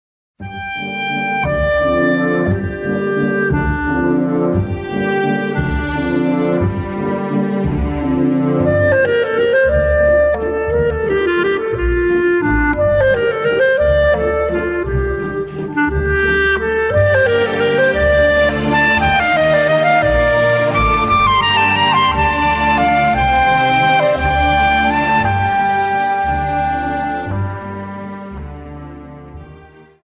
Dance: Viennese Waltz Song